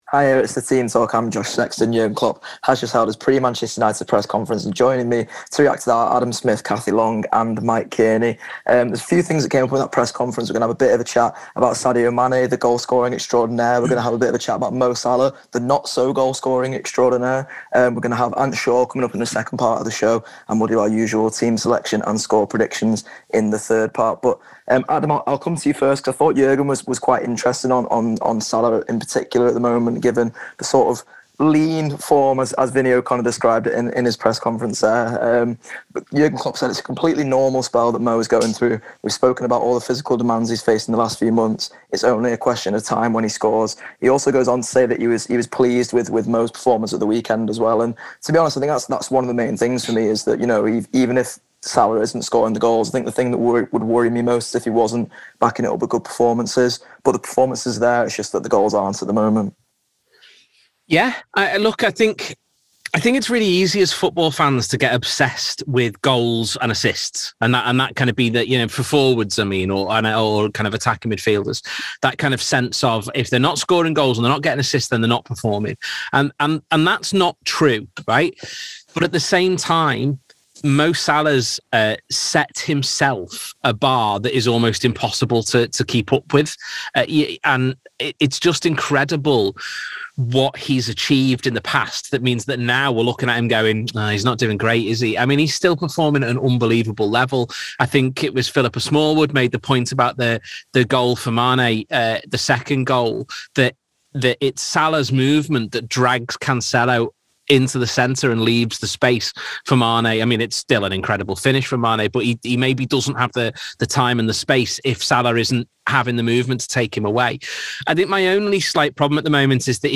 Below is a clip from the show – subscribe for more on the Liverpool v Manchester United press conference…